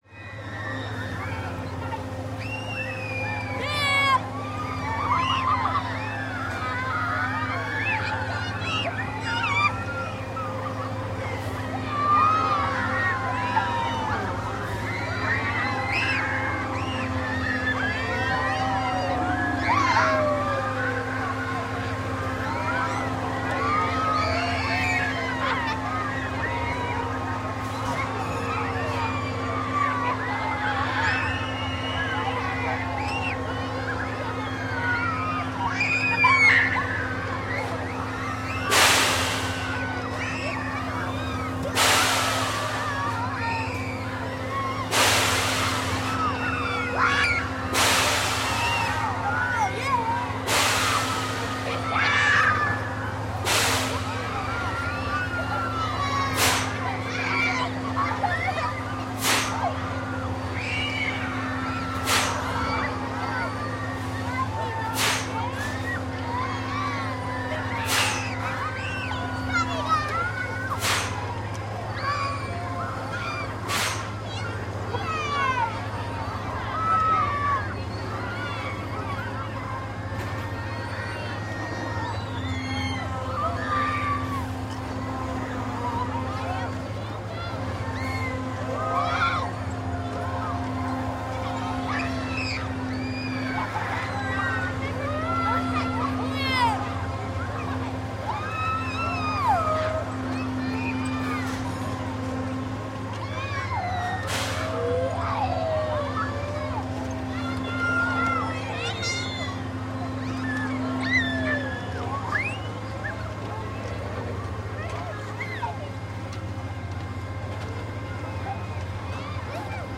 Дети радостно проводят время в парке развлечений